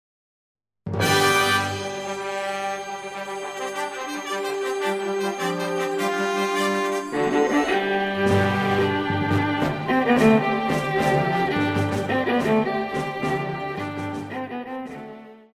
Pop
Viola
Band
Instrumental
World Music,Fusion
Only backing